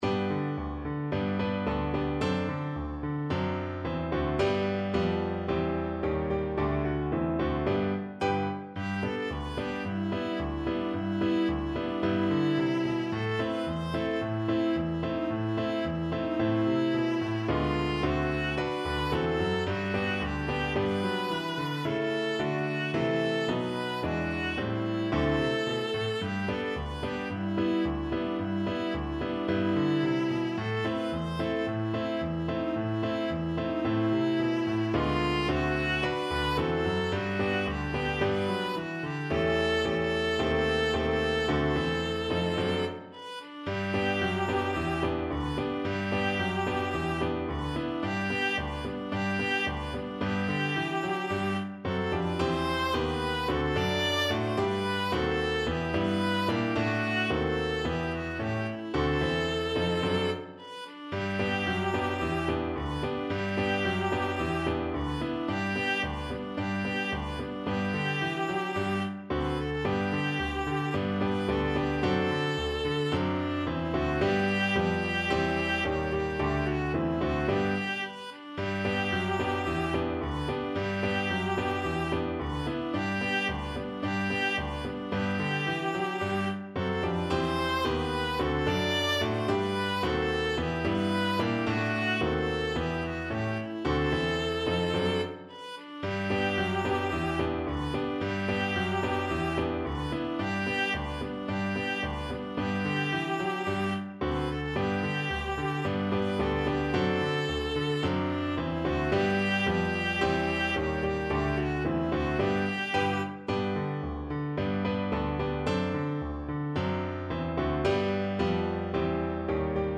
2/4 (View more 2/4 Music)
Moderato allegro =110
Classical (View more Classical Viola Music)